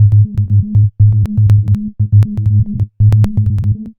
Planet Sub Ab 120.wav